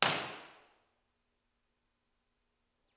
Description: Small sanctuary with balconies on 3 sides.
Halstead-Main-and-all-Distributed-middle-row.wav